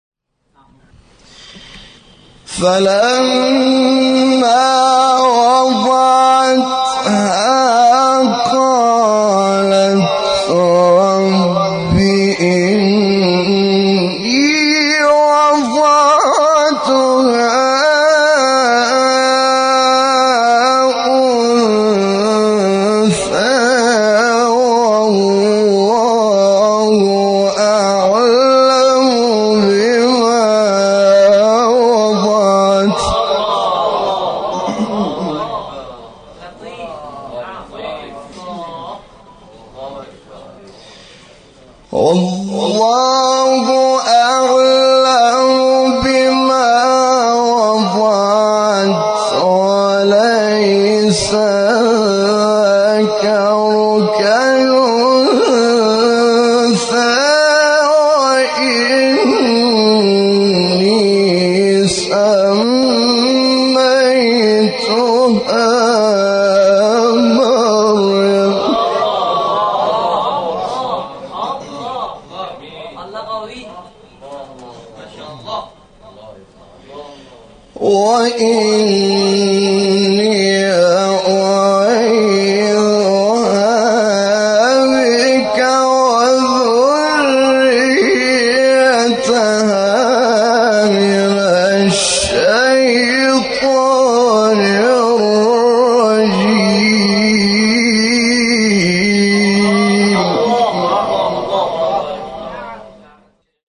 مقطع حامد شاکرنژاد به تقلید از شیخ محمد رفعت | نغمات قرآن | دانلود تلاوت قرآن